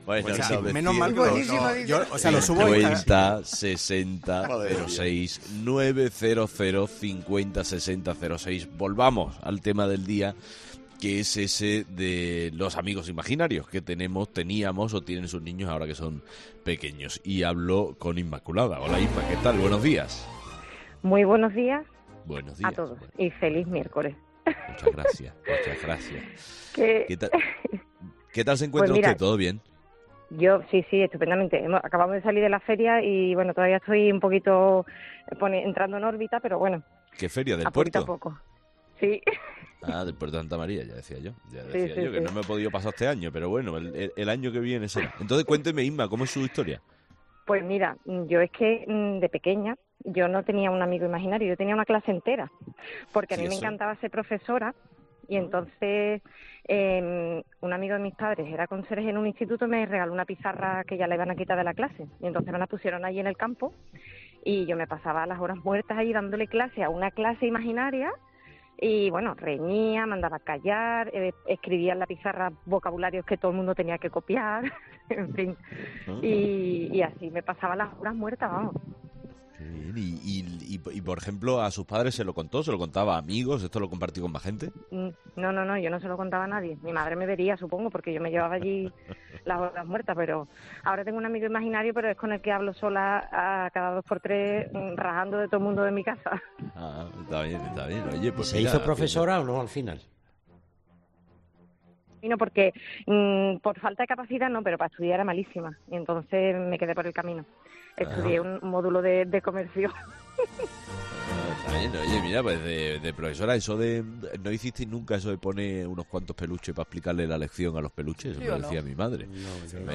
Charlamos con nuestros 'fósforos' sobre los amigos imaginarios. ¿Tenían de niños?